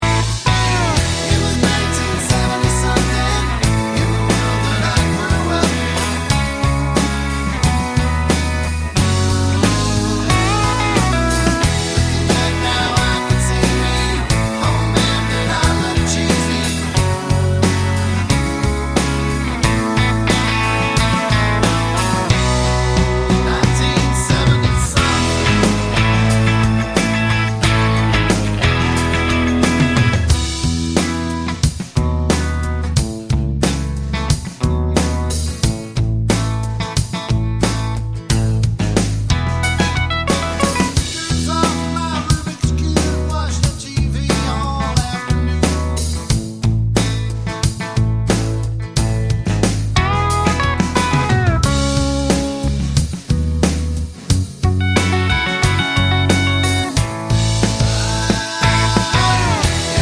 no Backing Vocals Country (Male) 3:18 Buy £1.50